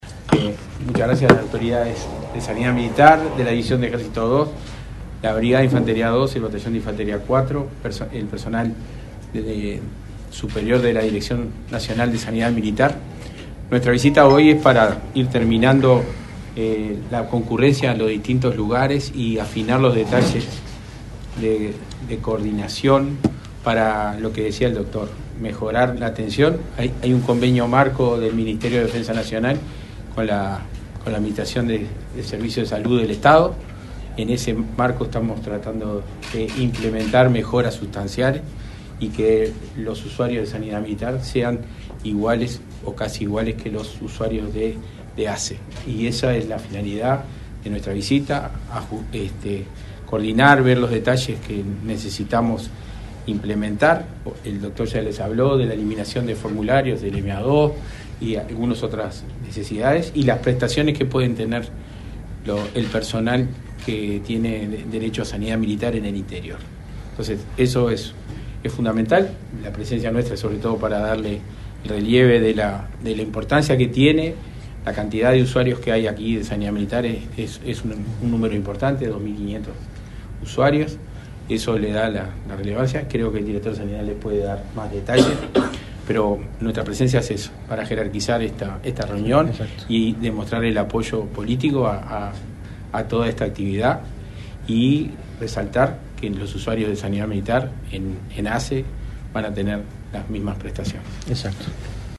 Palabras del subsecretario de Defensa, Rivera Elgue
Palabras del subsecretario de Defensa, Rivera Elgue 04/07/2024 Compartir Facebook X Copiar enlace WhatsApp LinkedIn El Ministerio de Defensa Nacional y la Administración Nacional de los Servicios de Salud del Estado (ASSE) firmaron un convenio para fortalecer la atención a usuarios de Sanidad Militar en los hospitales y policlínicas de la Red de Atención Primaria (RAP) de Colonia. Disertó en el evento el subsecretario de Defensa, Rivera Elgue.